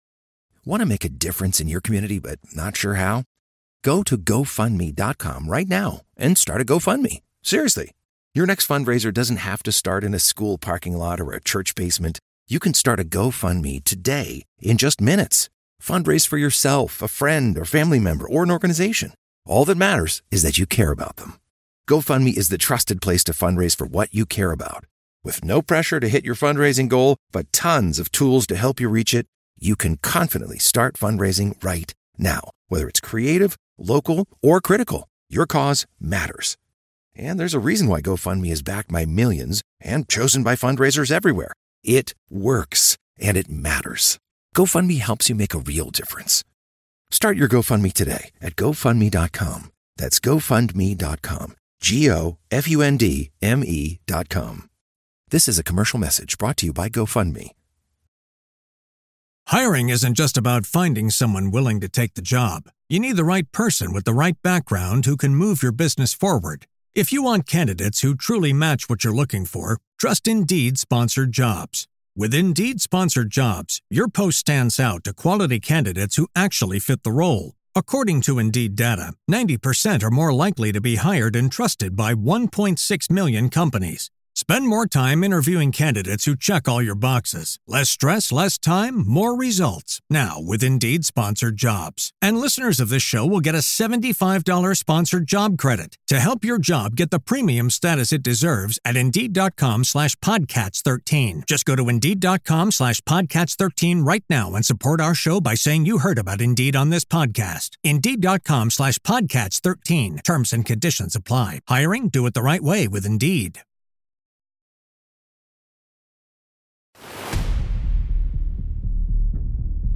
Auf der Rückfahrt aus der Schweiz erzähle ich, wie aus Langeweile im Hotelzimmer eine Cyber-Thriller-Idee wurde, warum ein Buch im Grunde nichts anderes ist als ein Projekt – mit Storymap, Meilensteinen, Character Guide und Location Guide  – und welche Parallelen es zu klassischen Projektmanagement-Methoden wie PRINCE2 oder SAFe gibt .